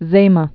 (zāmə, zämä)